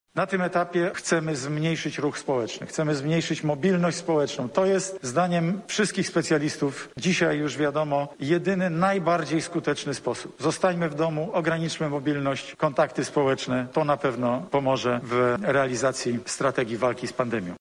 Pandemia nasila się więc i nasza odpowiedź musi być zdecydowana– mówił dzisiaj premier Mateusz Morawiecki: